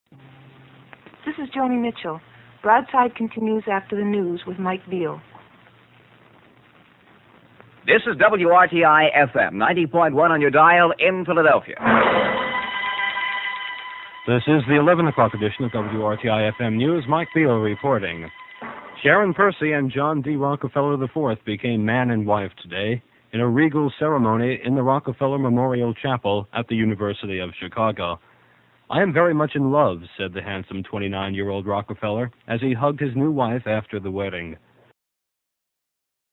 The news
promoed by folk singer Joni Mitchell